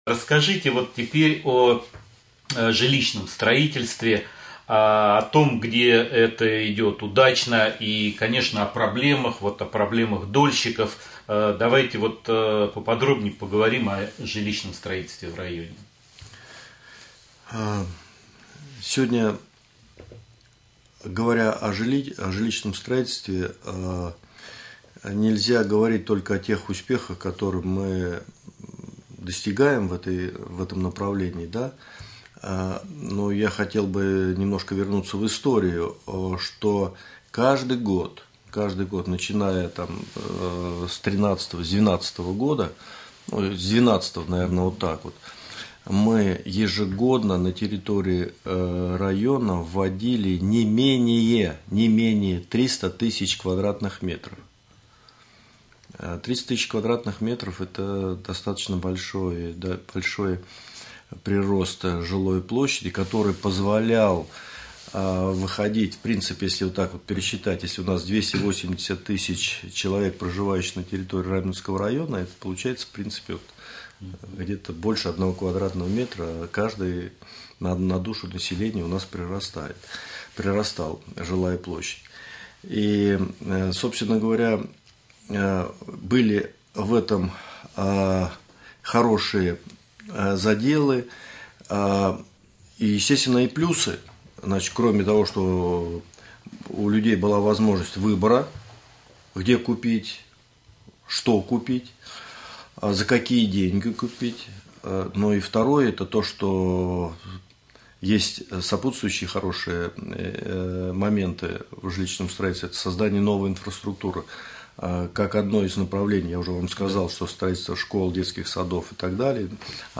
Ежегодно, начиная в 2012 года, на территории Раменского района вводят в эксплуатацию не менее 300 тысяч квадратных метров жилья, об этом в ходе прямого эфира на Раменском радио рассказал первый заместитель главы администрации Раменского района Николай Воробьев.